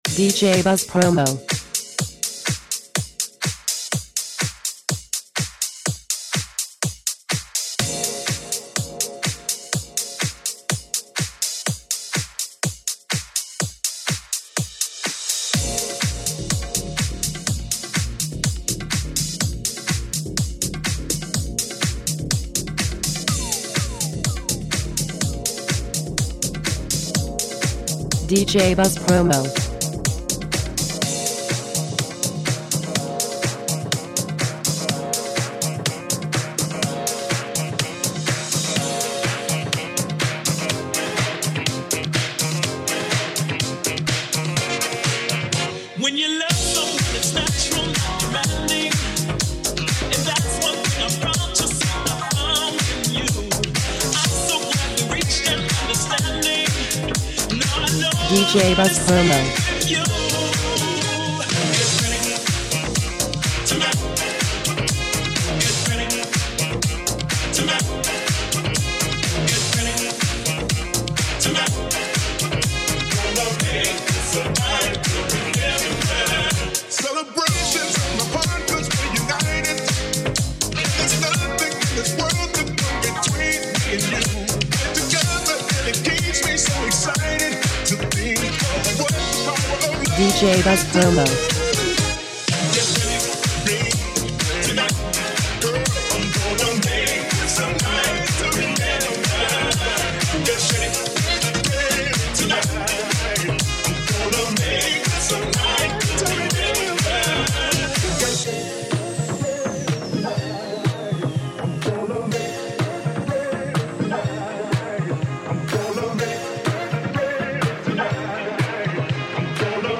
dynamic cover
Extended